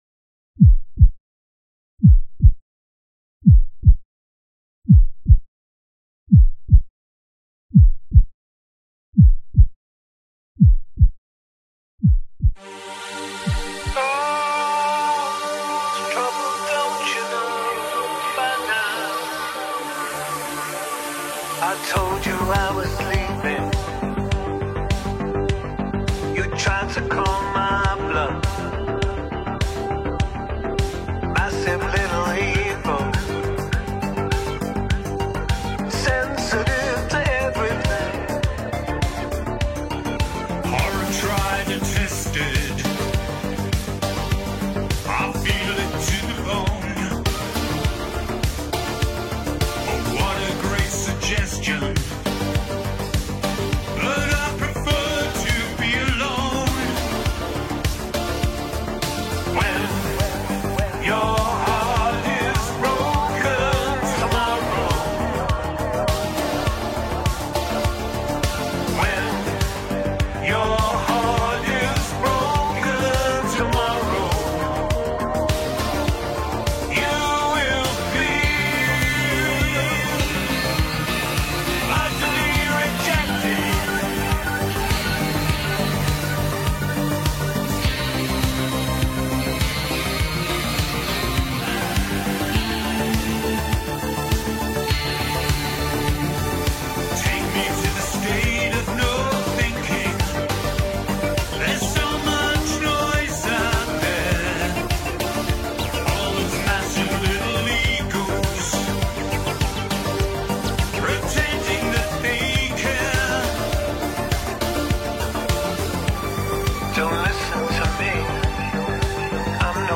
You can hear experience in every phrase.